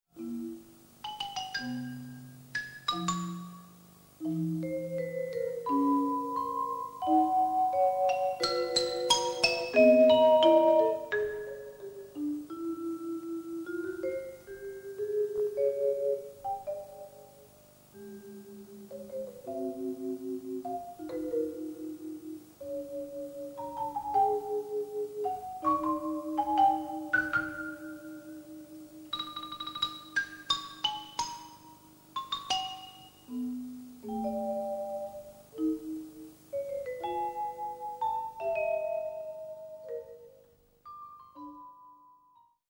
Suite f. Stabinstrumente (1985)
(Xyl, Vib, Marimba, Glocksp)